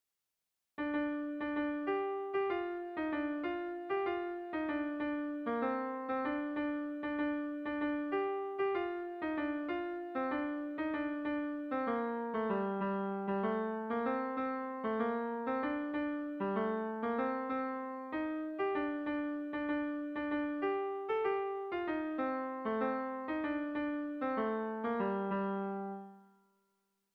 Sentimenduzkoa
Zortziko handia (hg) / Lau puntuko handia (ip)
ABDB